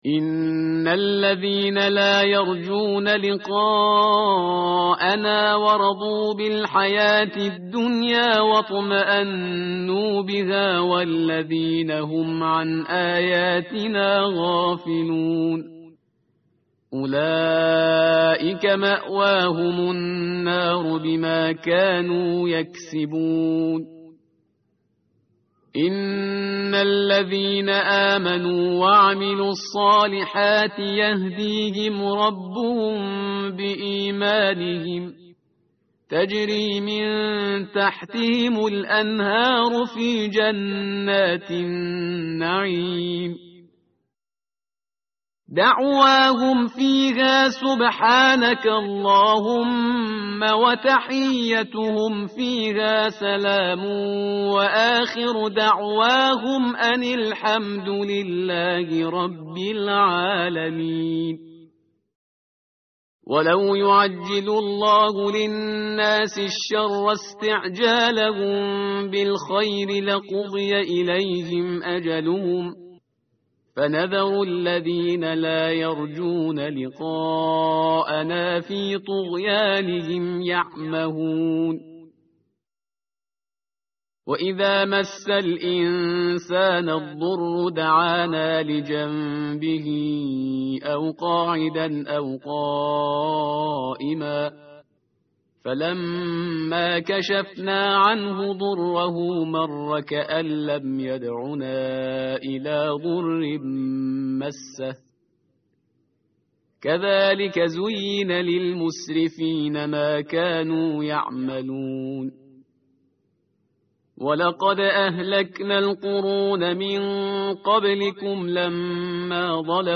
tartil_parhizgar_page_209.mp3